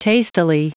Prononciation du mot tastily en anglais (fichier audio)